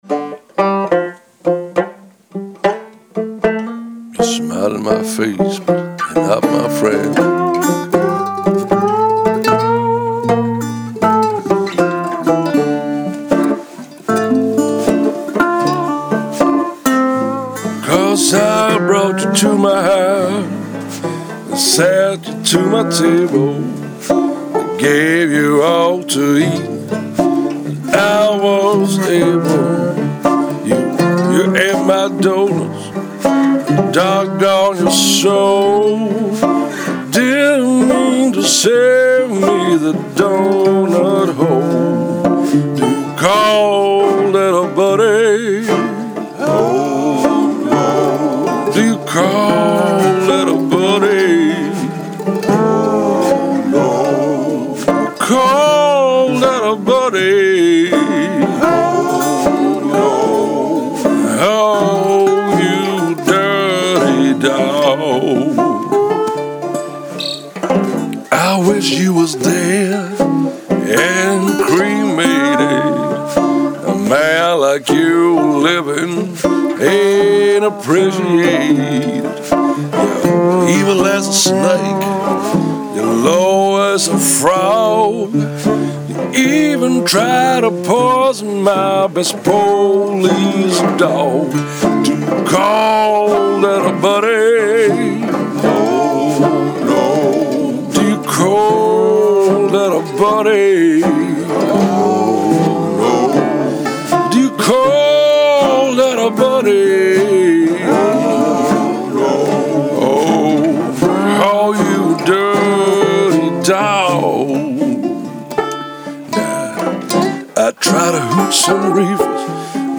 Recording blues on the go!!!
Just for the sake of it, I squeezed 10 tracks into the recording on my iPhone, hehe ..
Got a little better this time with everything but the lead vocals distort here and there..
I used only the supplied earpiece and the phone mic ..
Guitar banjo: Levin 1931
Resonatorguitar: Republic Highway 61
Mandolin: Levin 1950's
Foot-stomping
Drums(brushes!) Played on the banjo skin, haha!
Lead Vocals
3 Backing vocals
Kazoo..
Using ONLY the iPhone and the headphones that comes along, so simple!!